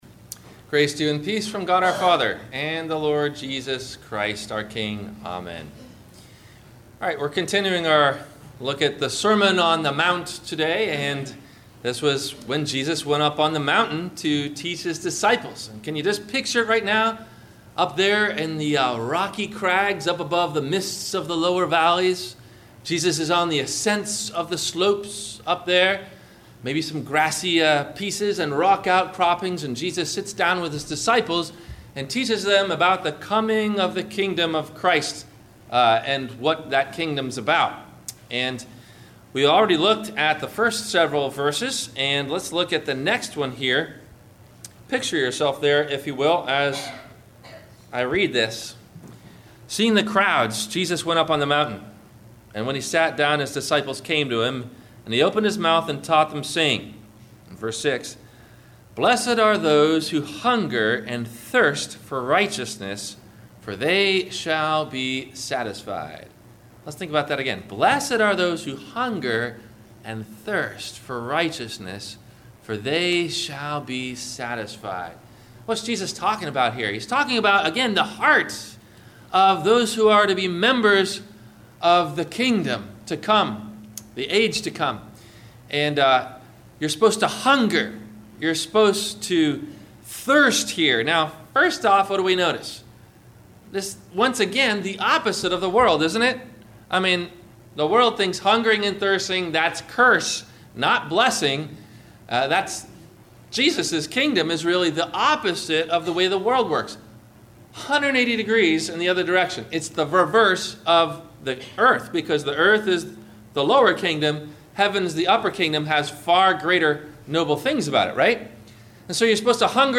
Sermon Series – “The Sermon on the Mount” (The Beatitudes) – Week 4